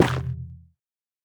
Minecraft Version Minecraft Version 1.21.5 Latest Release | Latest Snapshot 1.21.5 / assets / minecraft / sounds / block / shroomlight / break3.ogg Compare With Compare With Latest Release | Latest Snapshot
break3.ogg